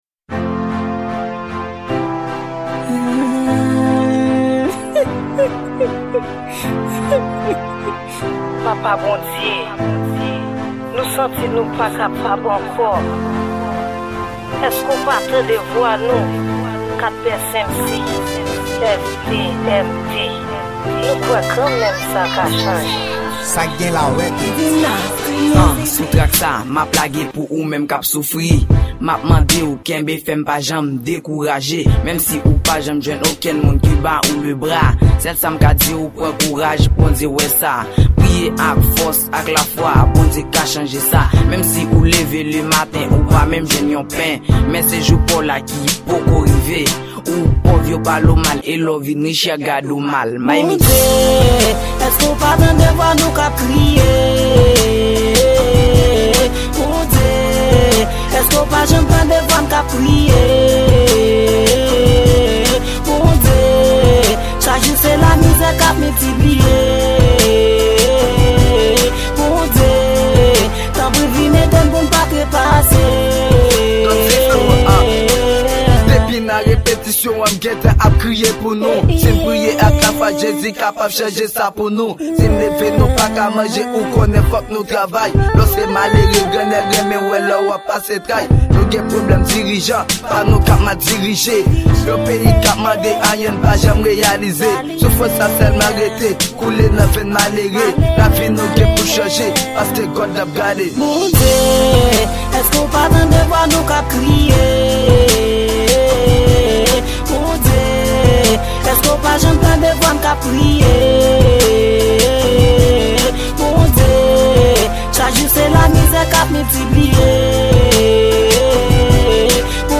Genre: Rap Gospel